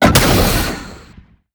JackHammer_3p_03.wav